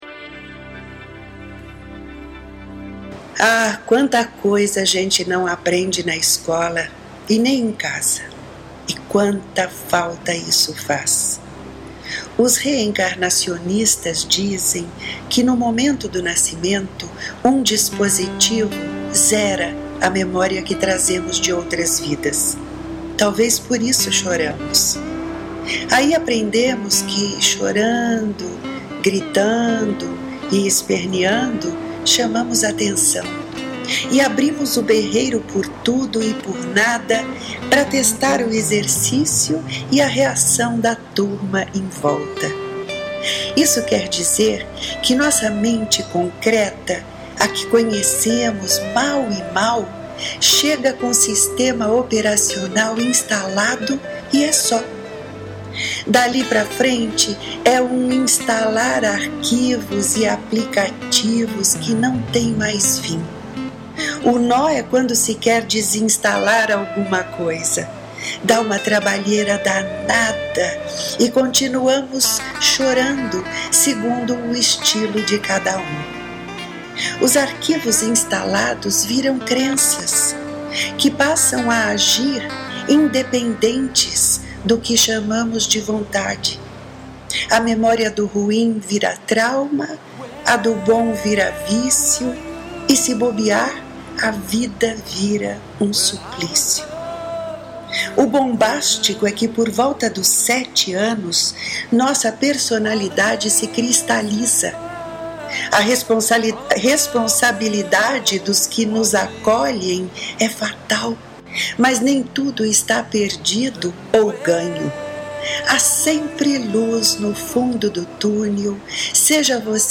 Ouça “De Mente” na voz e sonorizado pela autora